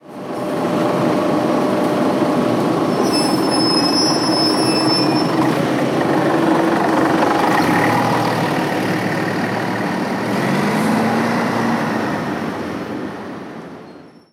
Salida de un autobús
Sonidos: Transportes